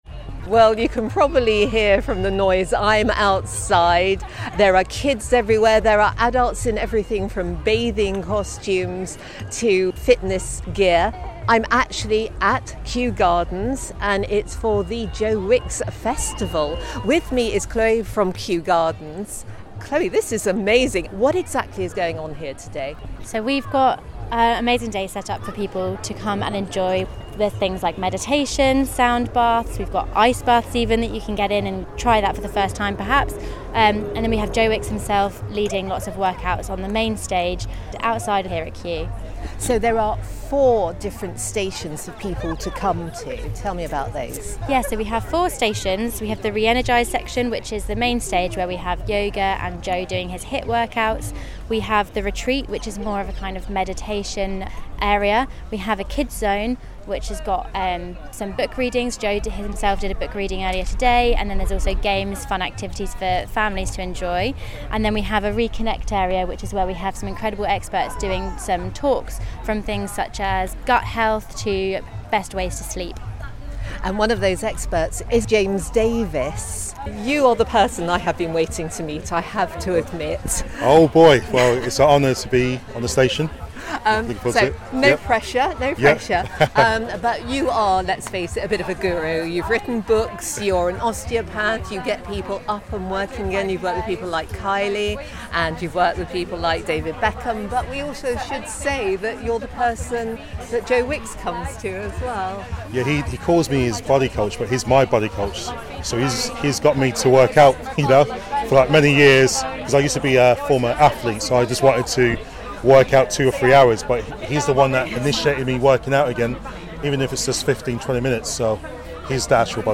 reports from Kew Gardens.